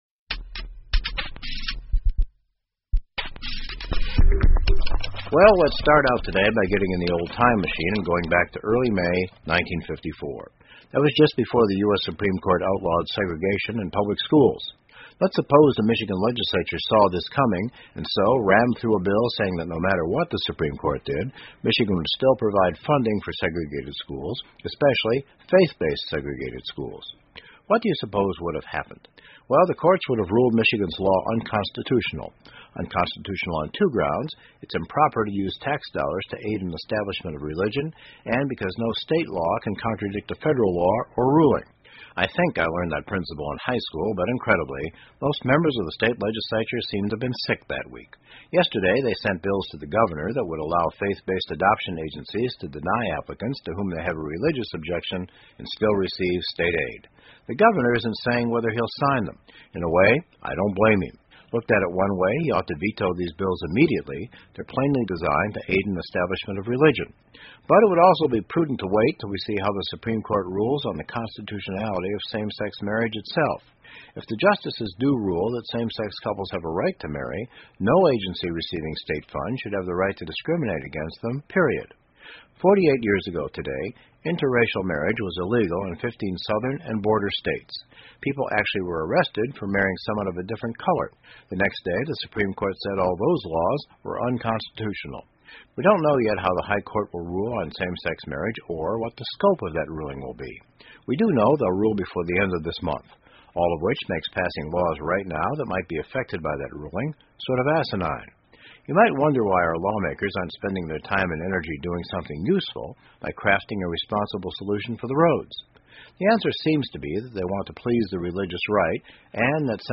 密歇根新闻广播 基于信仰的种族隔离学校 听力文件下载—在线英语听力室